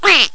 duck.mp3